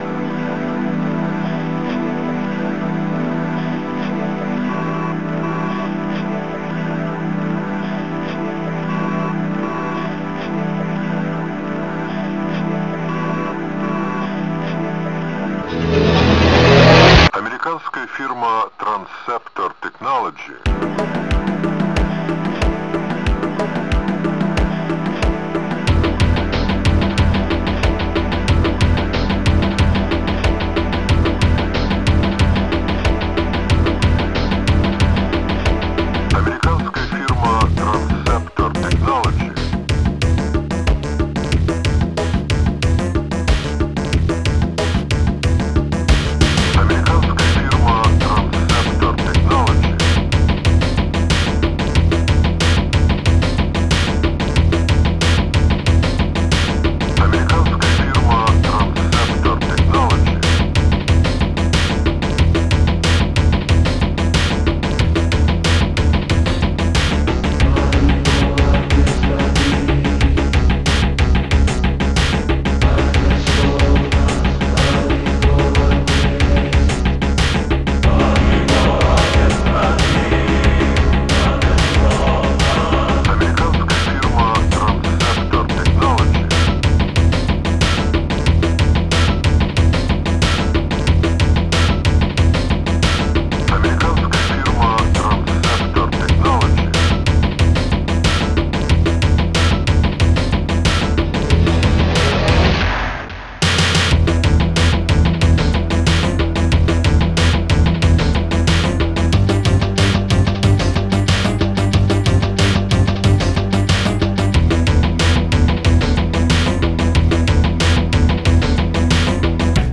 Enjoy this hardtrack
Bongo1
Bongobass
Megachoir4
Synth